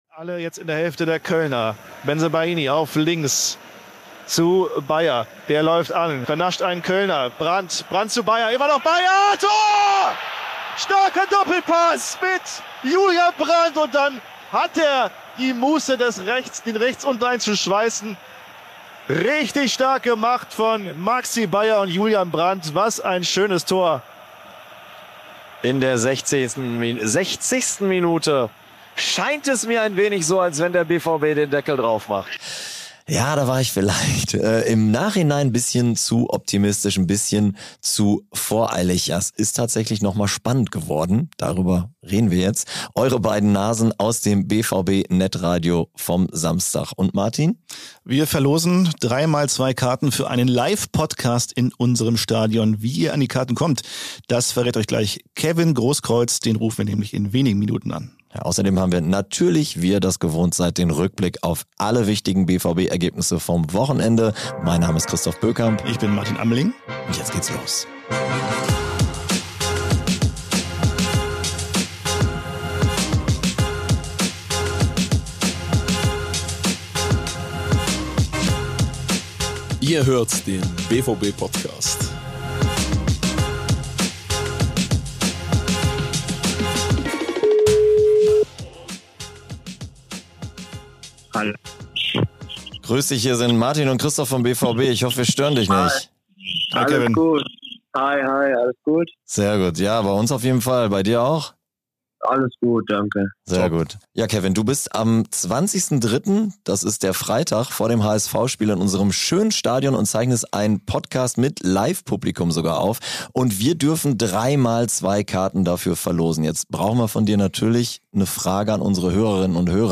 Waldemar Anton und Maximilian Beier haben wir exklusiv nach dem Abpfiff am Mikro. Und wir rufen in dieser Folge Kevin Großkreutz an, der vor dem HSV-Spiel einen Podcast mit Live-Publikum in Dortmund aufzeichnen wird.